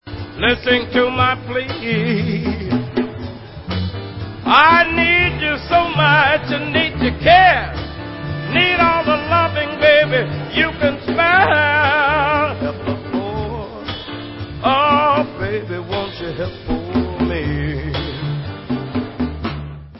sledovat novinky v oddělení Blues